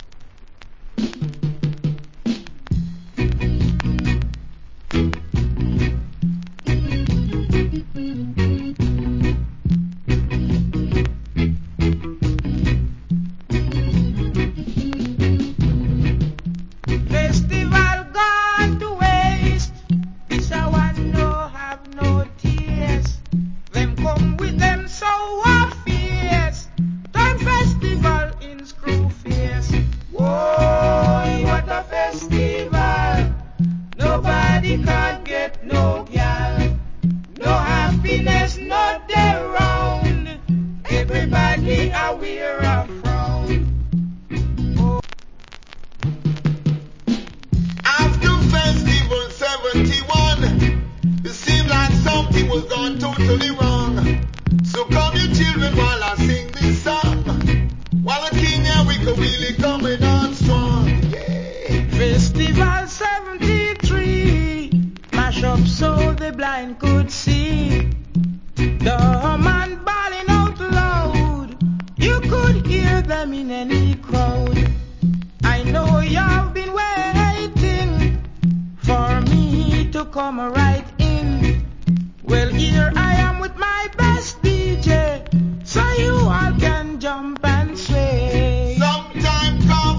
Old Hits Reggae.